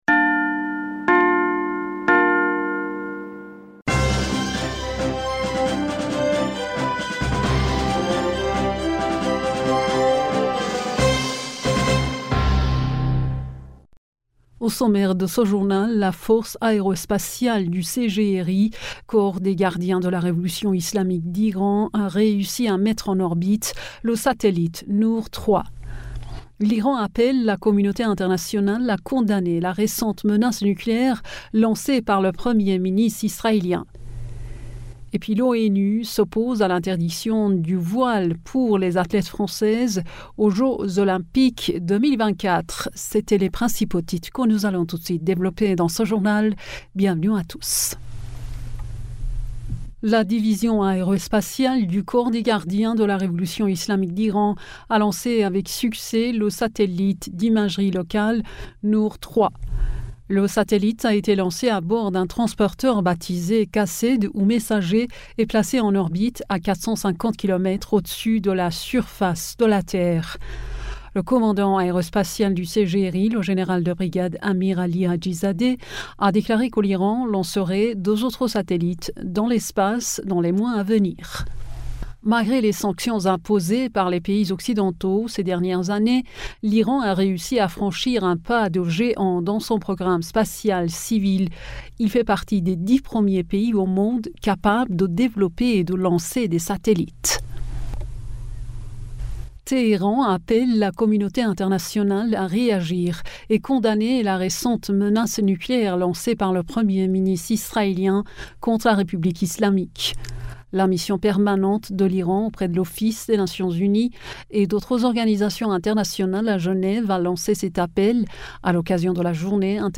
Bulletin d'information du 27 Septembre 2023